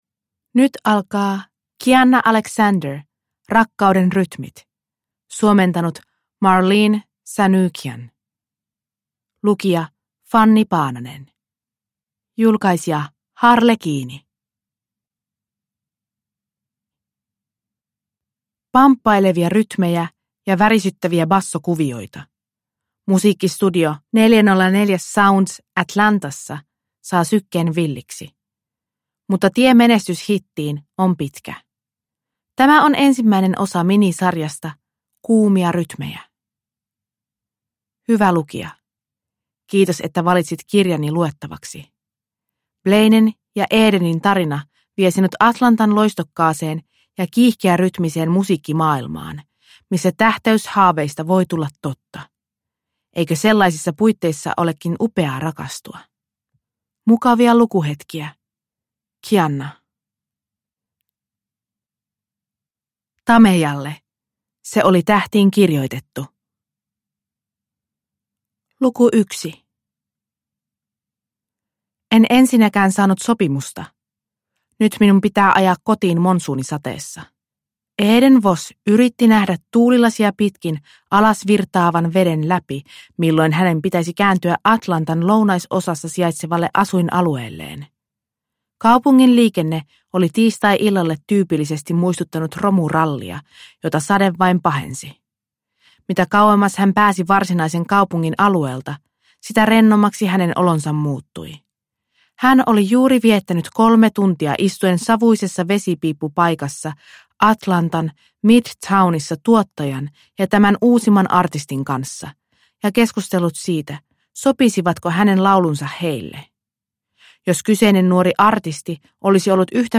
Rakkauden rytmit (ljudbok) av Kianna Alexander